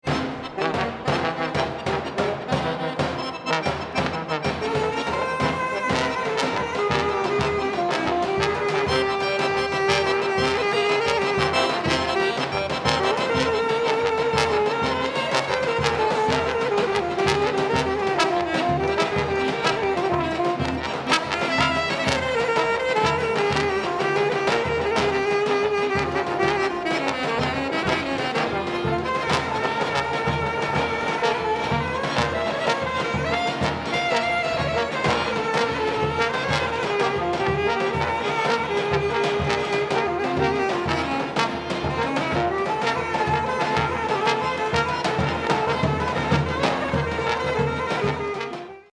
Extrait live